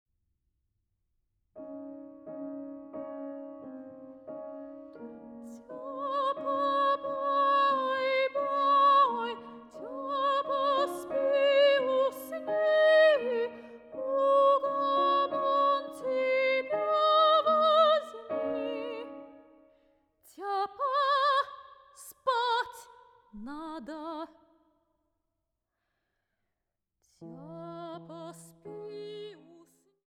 Sopranistin
Pianistin